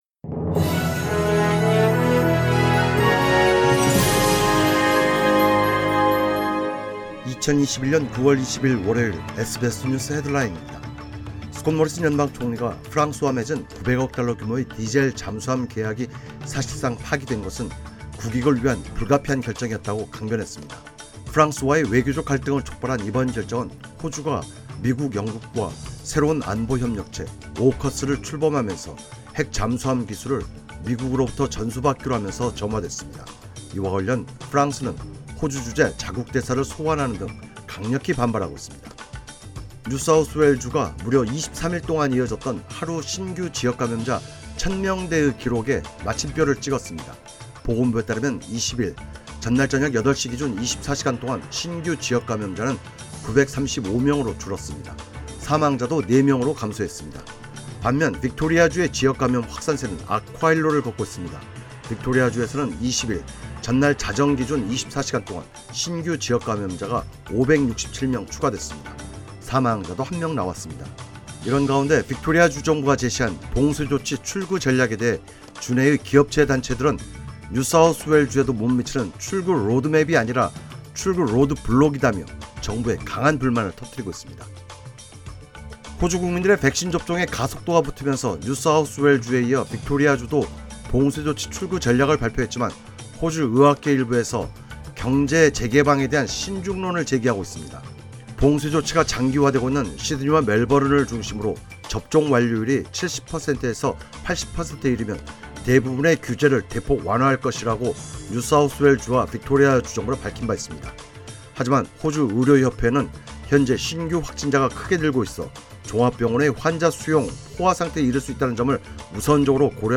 2021년 9월 20일 월요일 SBS 뉴스 헤드라인입니다.